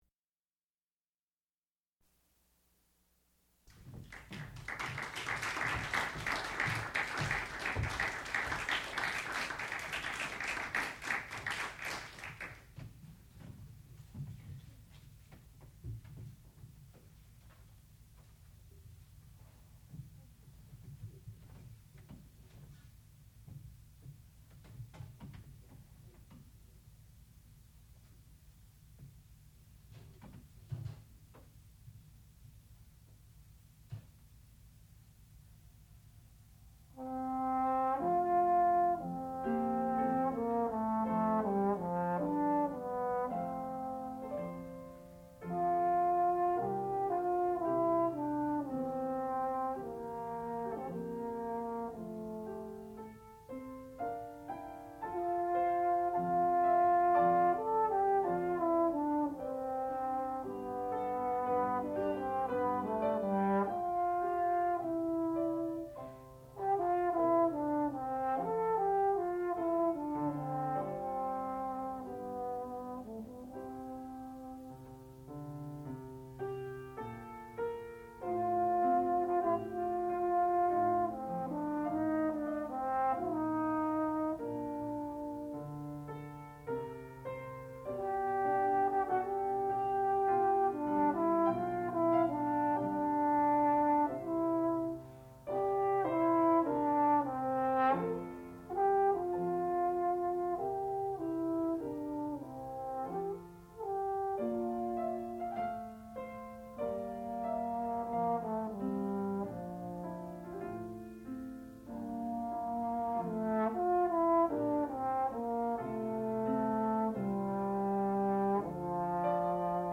sound recording-musical
classical music
trombone
piano
Qualifying Recital